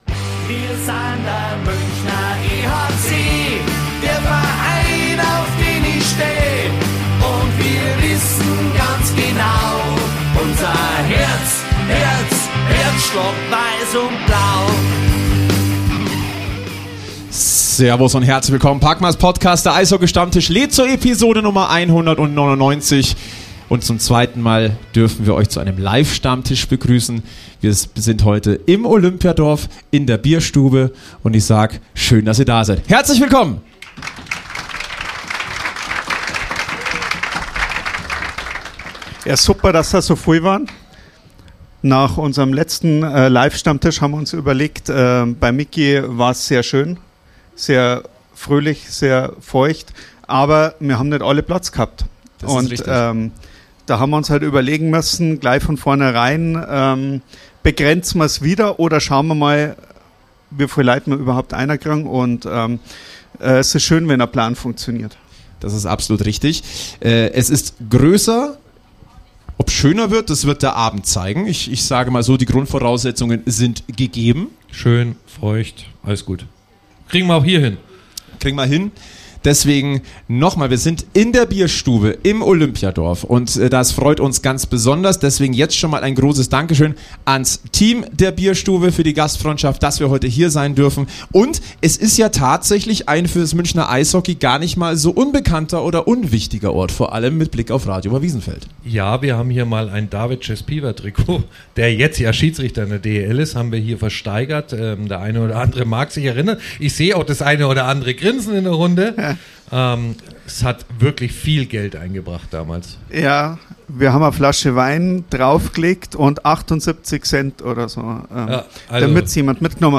Live-Stammtisch, die Zweite! Vor zahlreichen Hörerinnen und Hörern in der Bierstube besprechen wir die Ausgangslage des EHC Red Bull München vor den letzten acht Spielen der DEL-Hauptrunde mit bemerkenswerten Statistiken, die prognostizierte Auswirkung der Olympia-Unterbrechung der Liga auf den Eishockeyclub und die Konkurrenz und thematisieren die luxuriöse Goalie-Situation am Oberwiesenfeld. Dazu freuen wir uns über steigende Fan-Zahlen bei den Auswärtsspielen des EHC, haben neue Entwicklungen über den alten EHC München e.V. und sprechen mit den Vorständen des frisch gegründeten Eishockey-Fanprojekt München über ihre Beweggründe, Ideen und Ziele für die Kufenpower von der Isar.